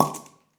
Corkscrew Wine Bottle Open Sound
household